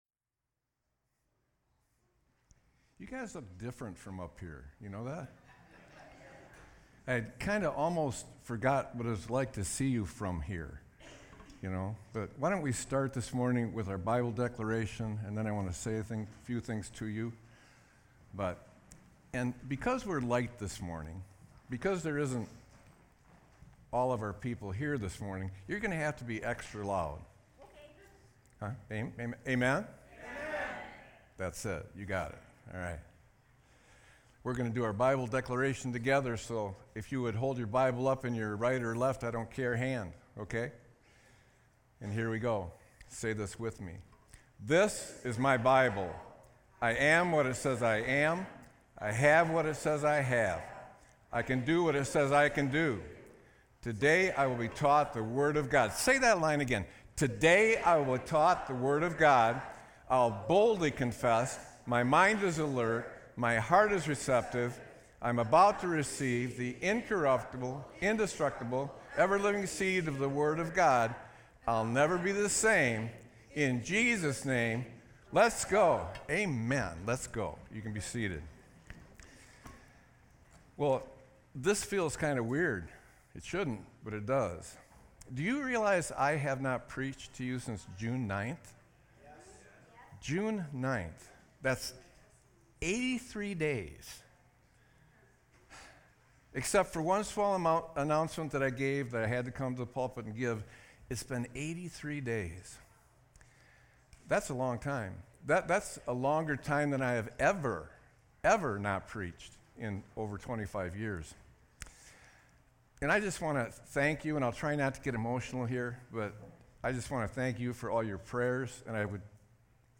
Sermon-9-01-24.mp3